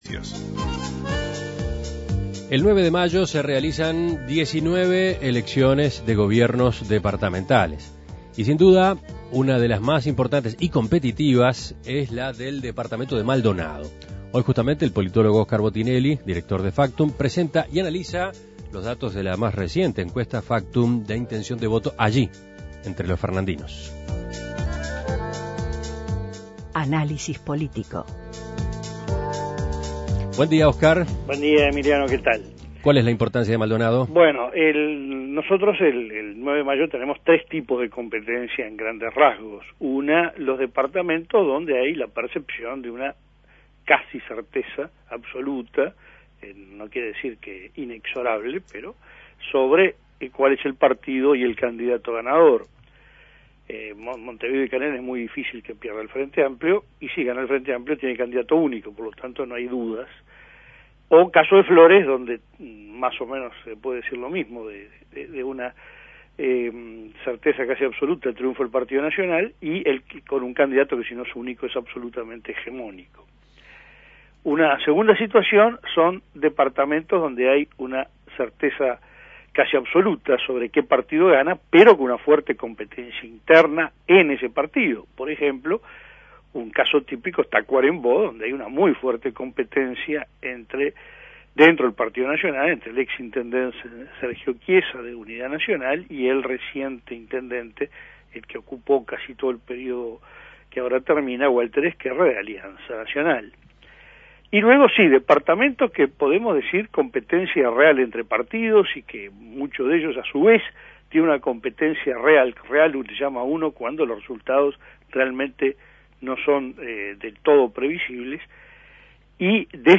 Análisis Político ¿Qué piensan votar en Maldonado el 9 de mayo para gobierno departamental?